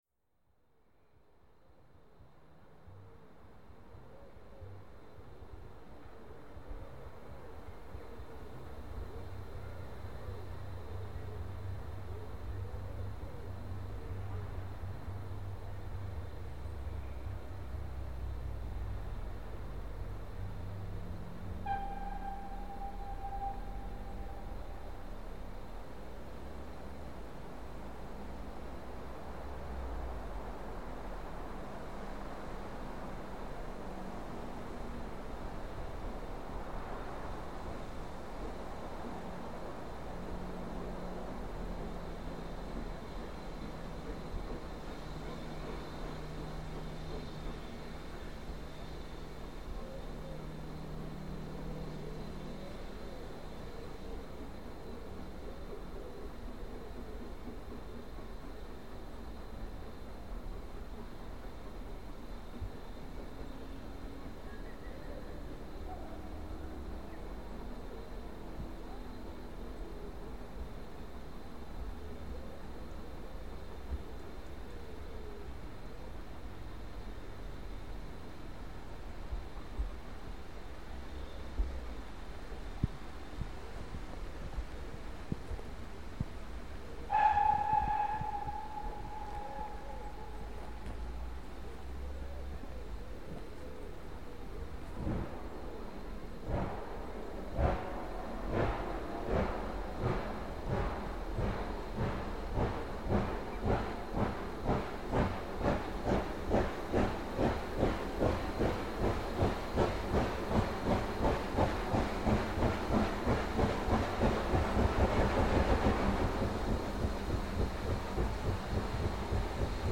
Zugkreuzung im Bahnhof Levisham: